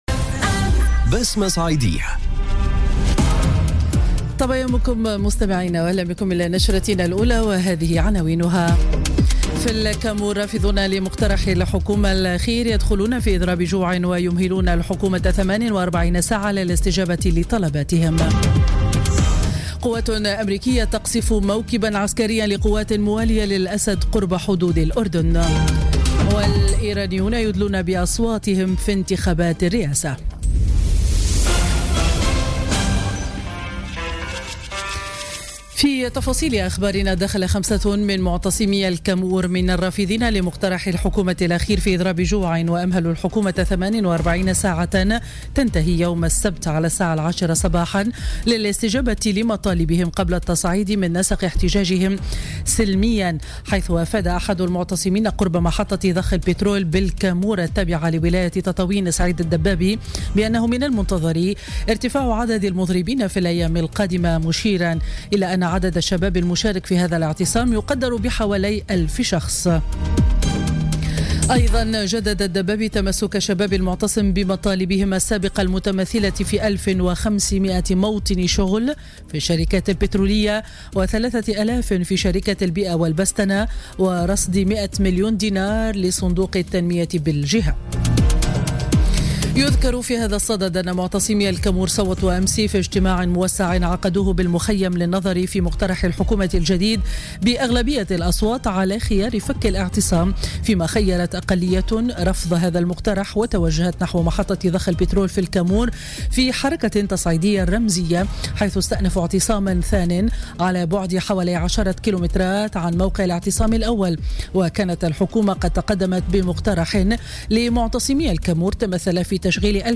نشرة أخبار السابعة صباحا ليوم الجمعة 19 ماي 2017